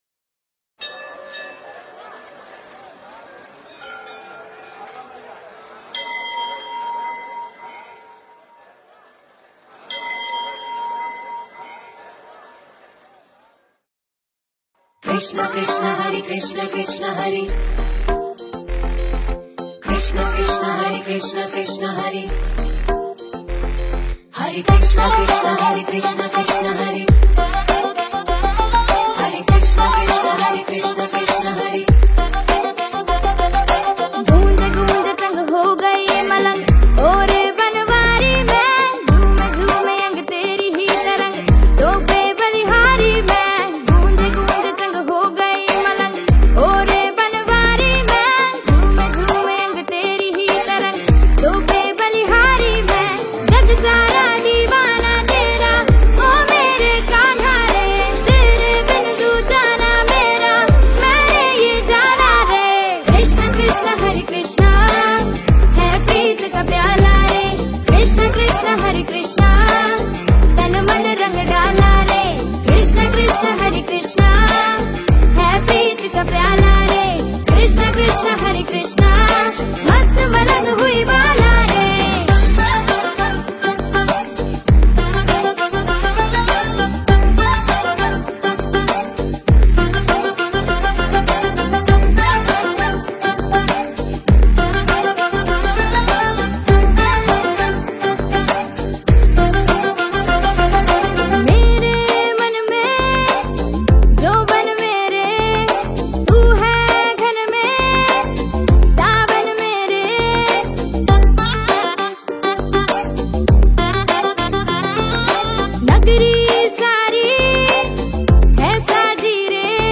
Bhakti Songs